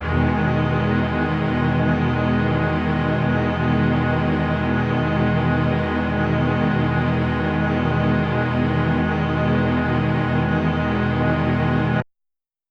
SO_KTron-Ensemble-Emaj7.wav